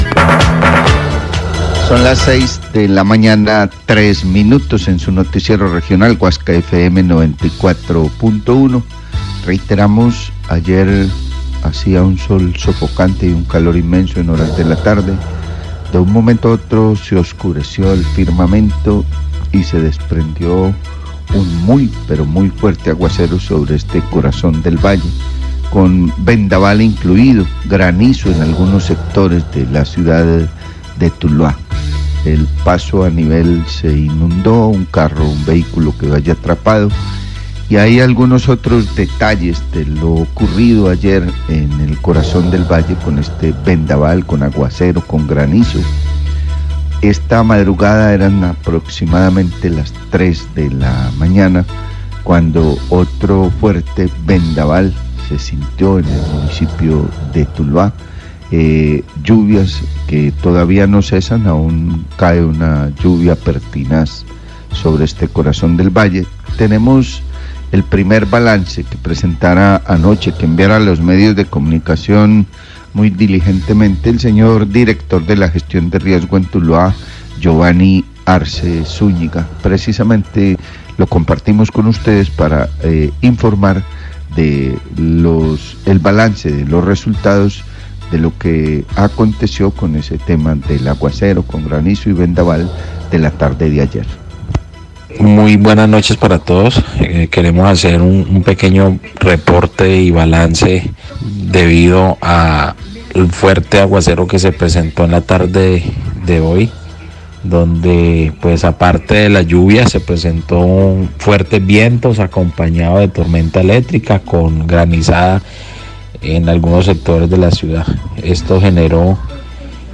Dir. Oficina Gestión del Riesgo de Tuluá entrega balance de emergencias por fuertes lluvias
Radio